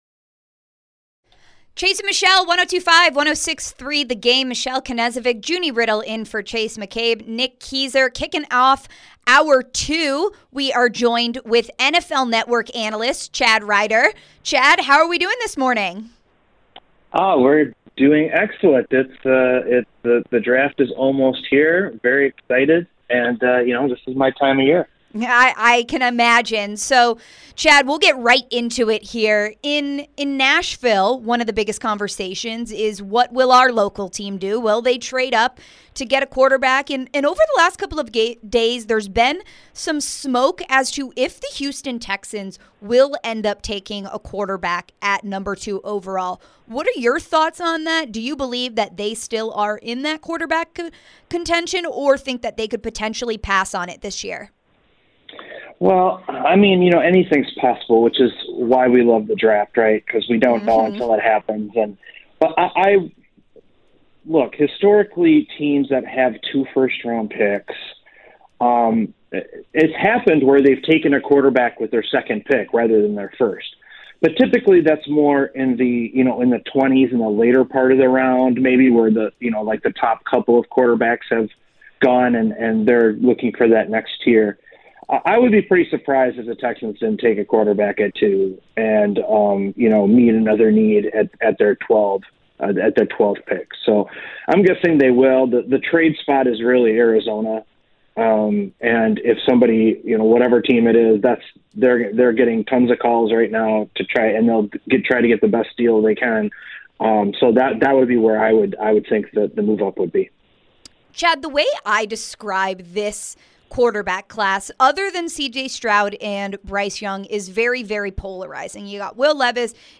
Interview (4-12-23)